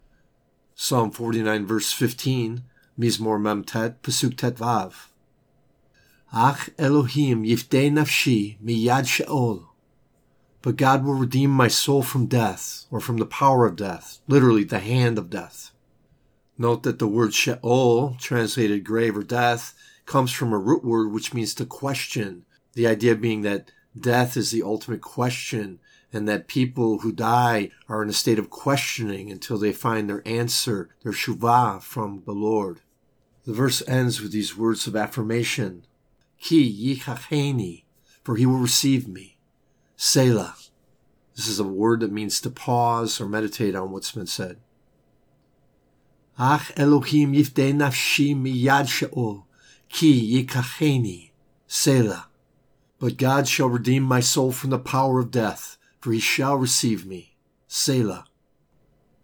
JoPsalm 49:15 reading (click):